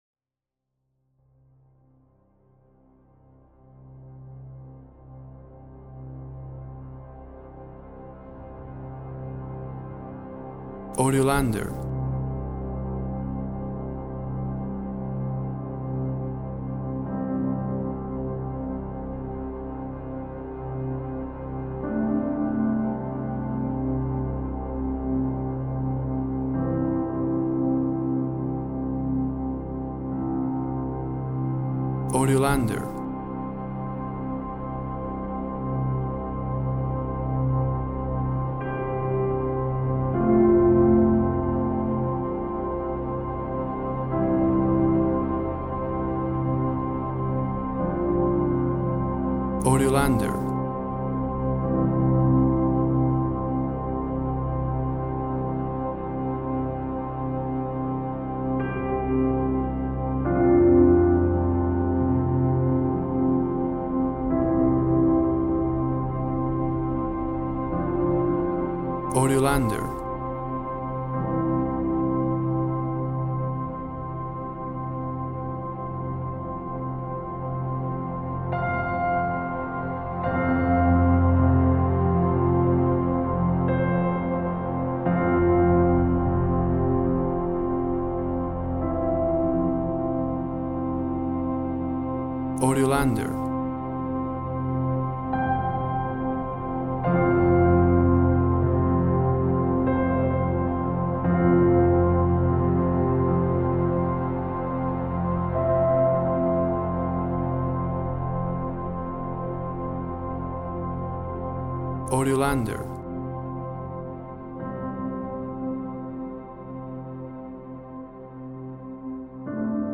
Dreamlike, meditative music.